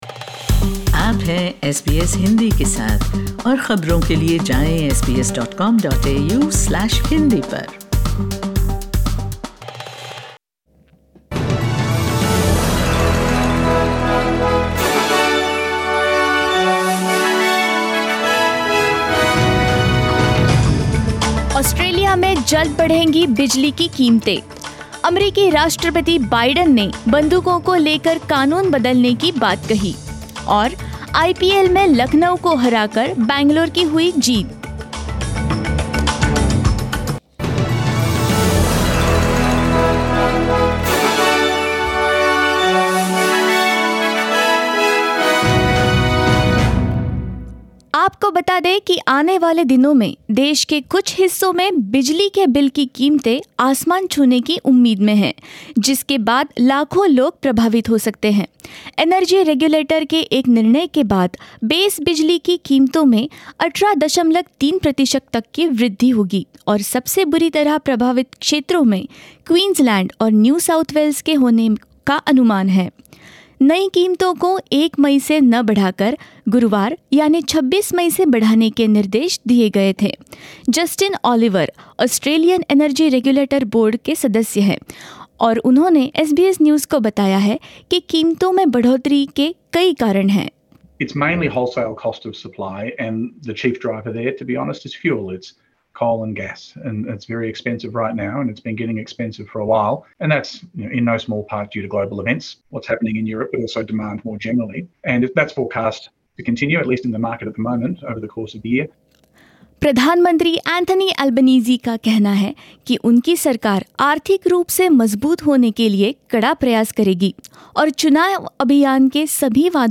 In this latest SBS Hindi bulletin: Australia's base electricity prices to increase by up to 18.3 per cent, adding to cost of living pressures; Julian Simmonds becomes the latest Liberal candidate to concede defeat to Greens; Indigenous community calls for a reparations scheme for Stolen Generations survivors; In sports, Rafael Nadal wins 300th Grand Slam match and more.